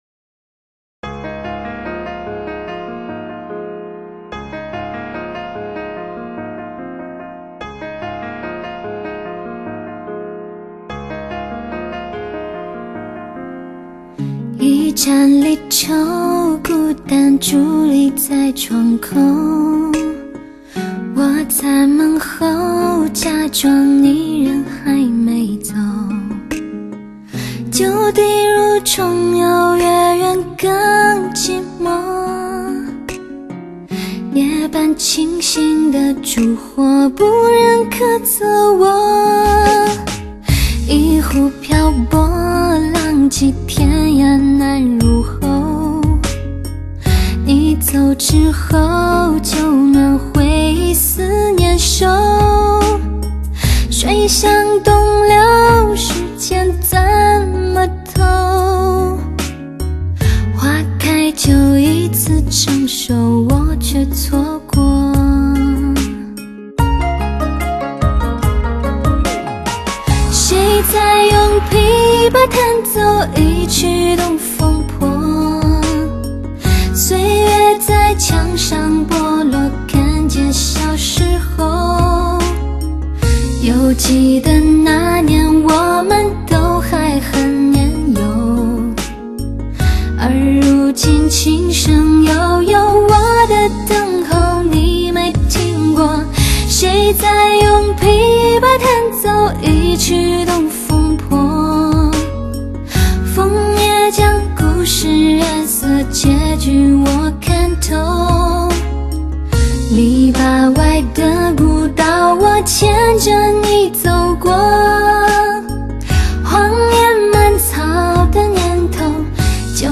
原版发烧碟的母带进行调声和均化，强化了听觉与整体效果，延续绝响的精彩。